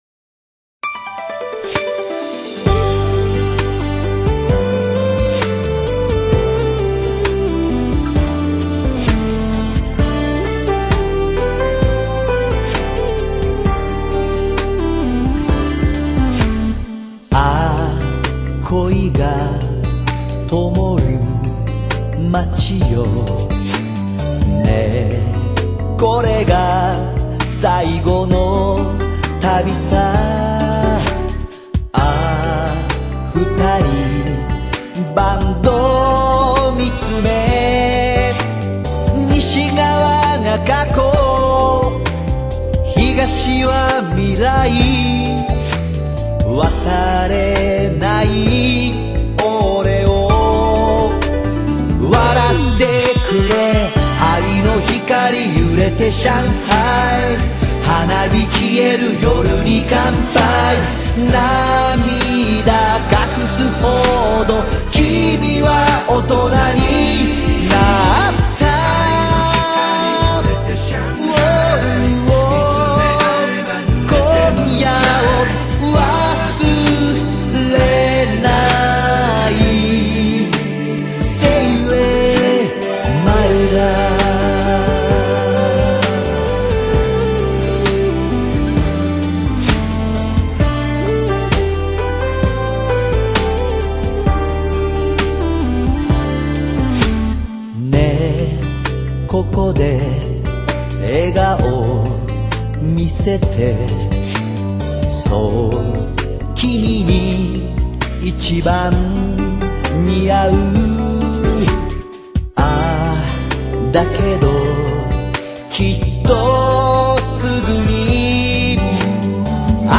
采用了流畅的J-BLUES配合他一贯的沙哑风格